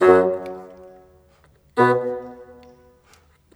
Rock-Pop 01 Bassoon 02.wav